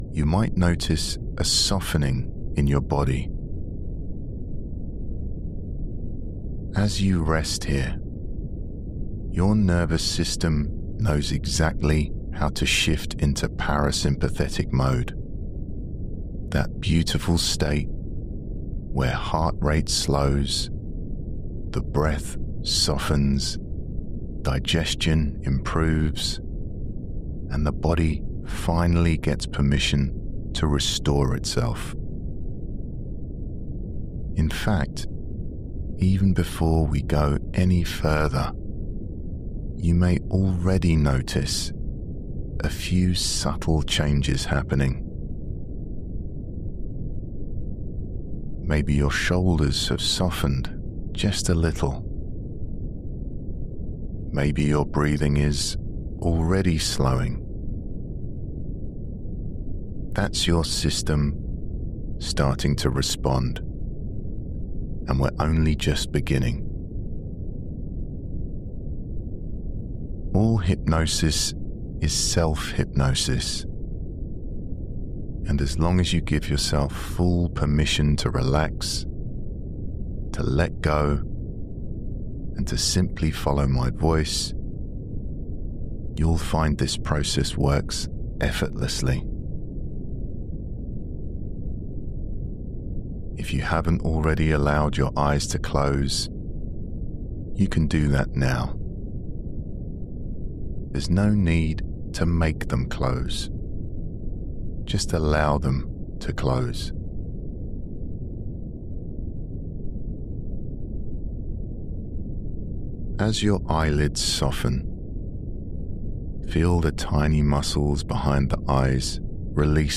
Sleep Hypnosis for Exhaustion, Fatigue and Burnout - Powerful Guided Deep Sleep Meditation
Guided_Deep_Sleep_Meditation.mp3